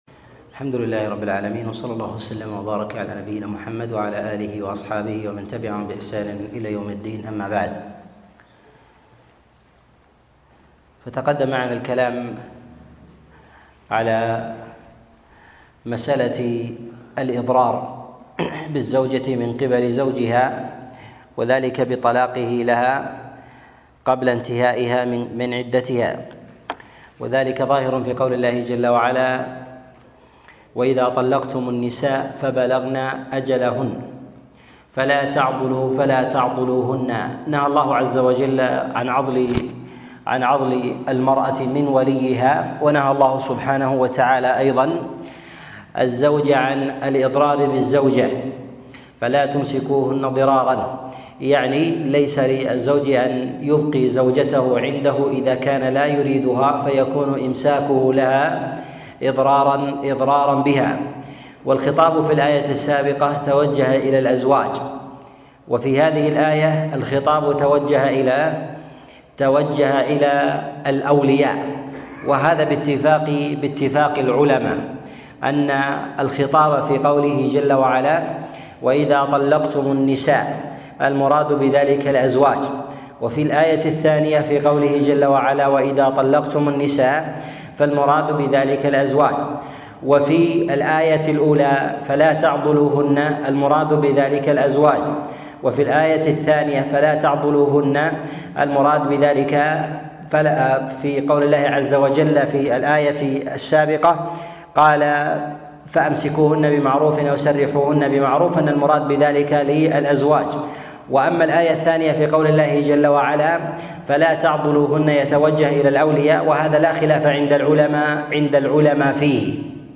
تفسير سورة البقرة 33 - تفسير آيات الأحكام - الدرس الثالث والثلاثون